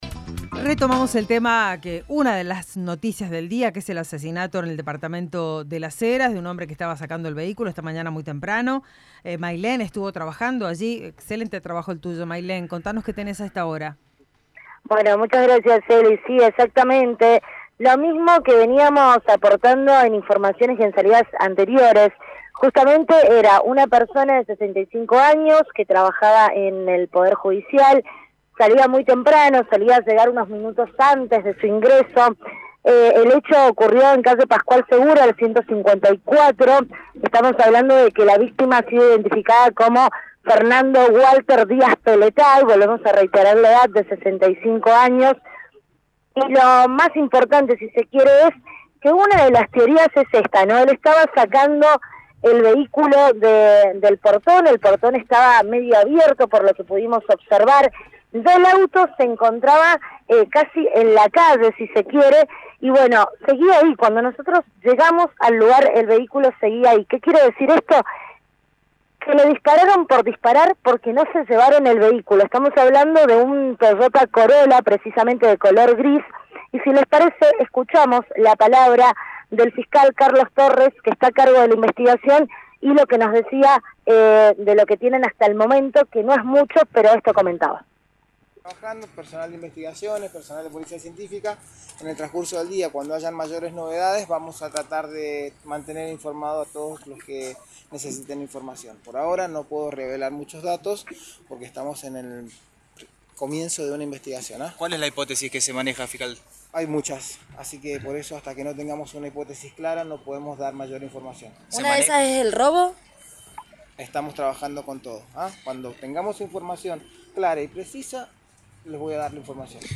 Móvil de LVDiez- Crímen empleado judicial en Las Heras
El Fiscal de Homicidios Carlos Torres dijo que investigan muchas hipótesis